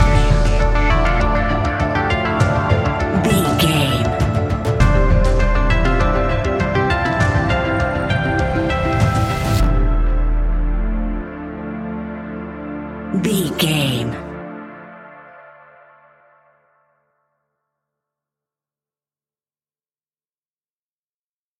Aeolian/Minor
D
tension
ominous
dark
suspense
eerie
synthesiser
drums
percussion
horror music